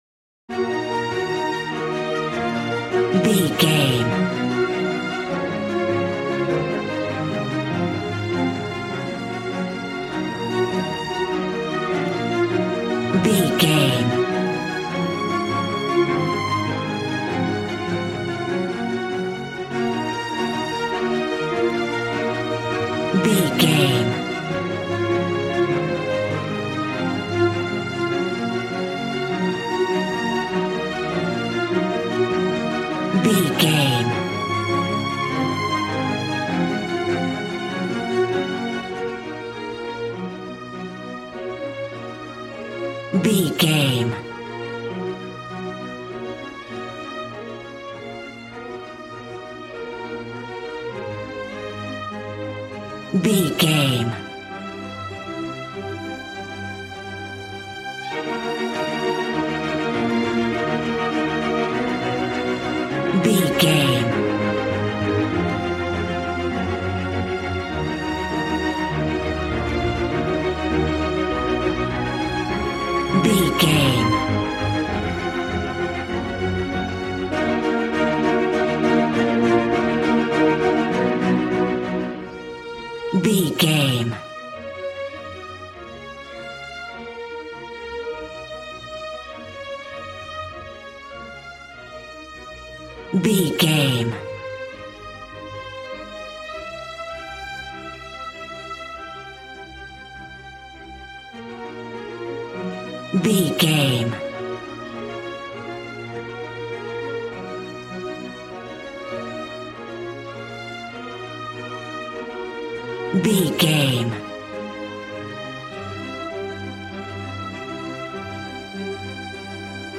Regal and romantic, a classy piece of classical music.
Aeolian/Minor
E♭
regal
strings
brass